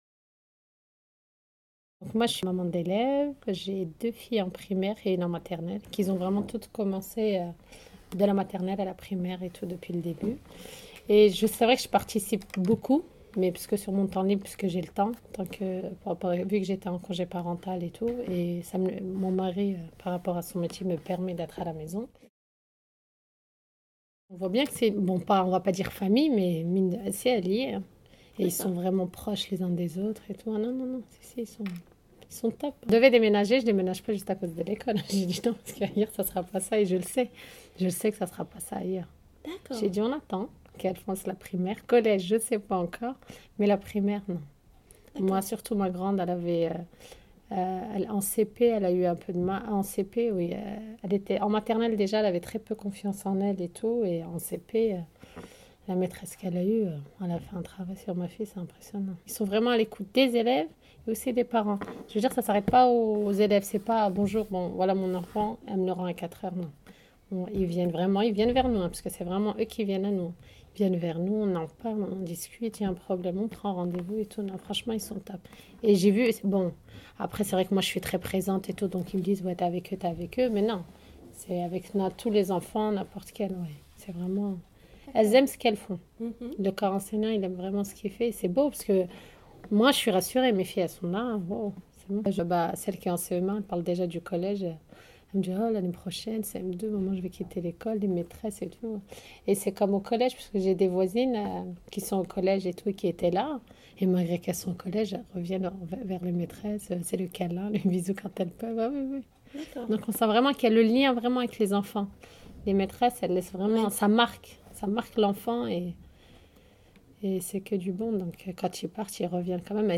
Nous leur avons posé trois questions auxquelles elles ont accepté de répondre : concernant la relation entre l'école et les familles, les devoirs à la maison, et la prise en charge de la difficulté scolaire.